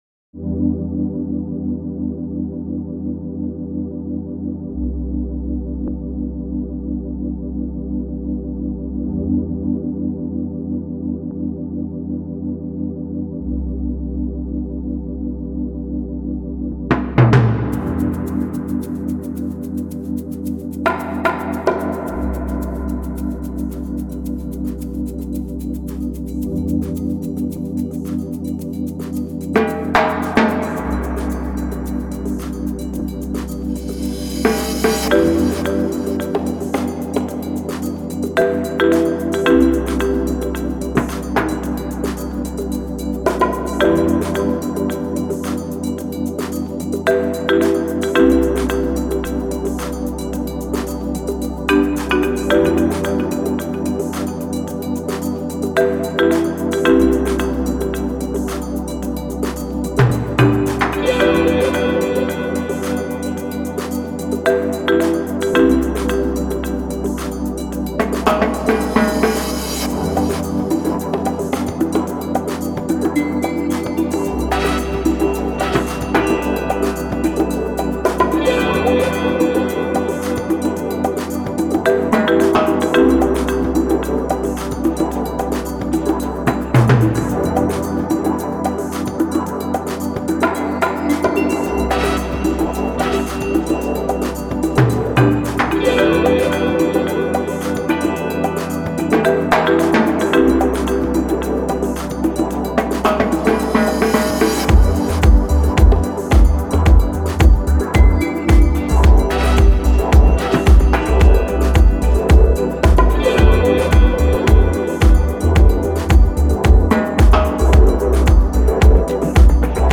Categories : funk , nu disco